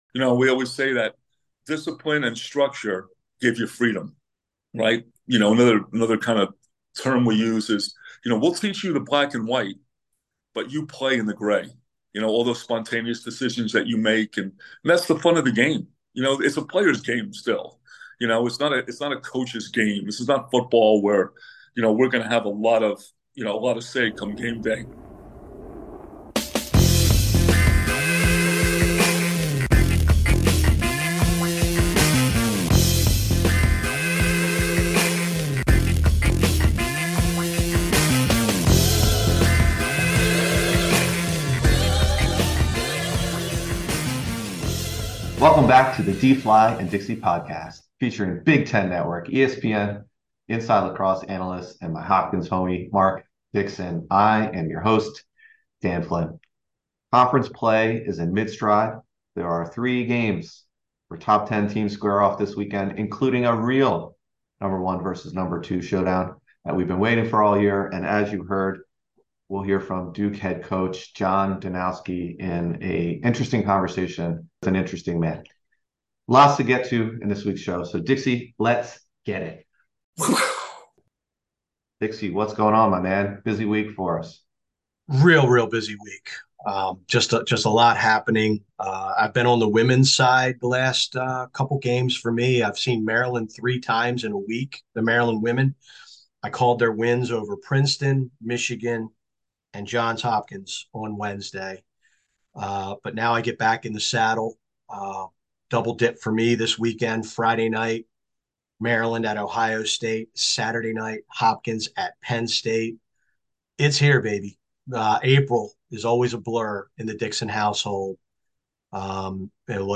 GIVE & GO In an Easter-inspired Give & Go, the fellas discuss Easter holiday traditions, including best and worst Easter basket items.